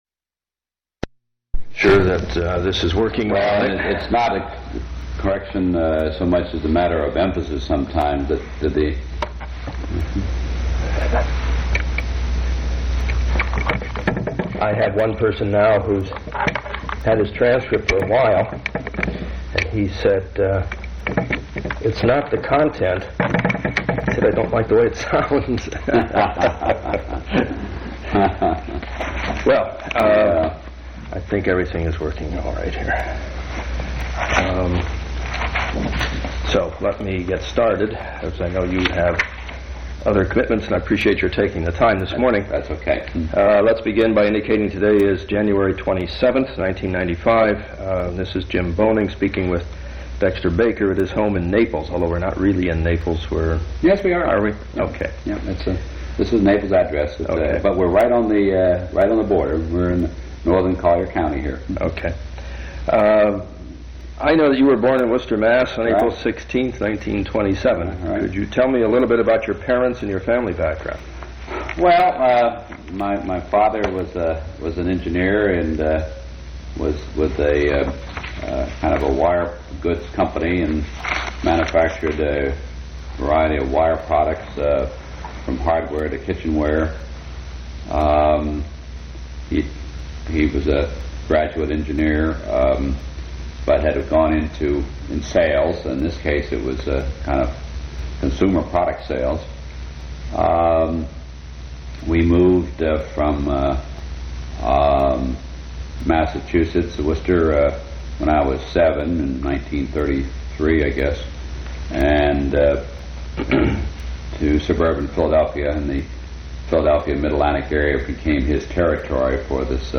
Complete transcript of interview